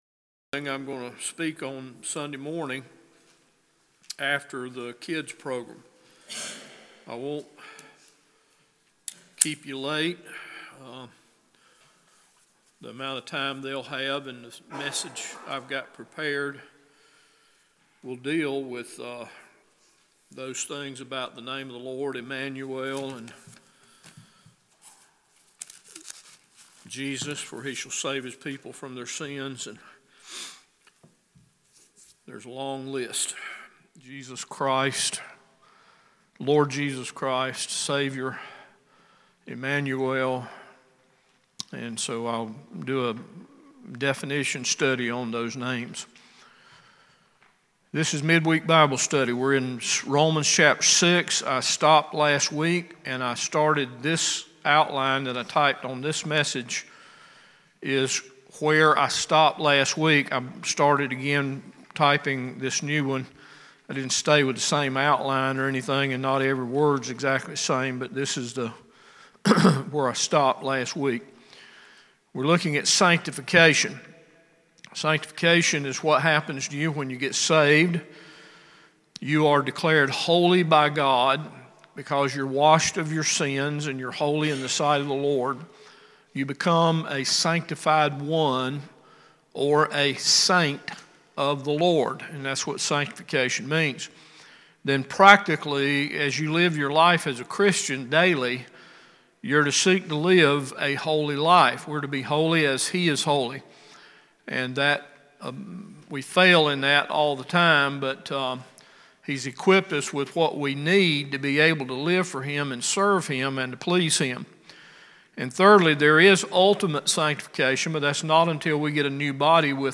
Romans Bible Study 9 – Bible Baptist Church